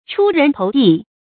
注音：ㄔㄨ ㄖㄣˊ ㄊㄡˊ ㄉㄧˋ
出人頭地的讀法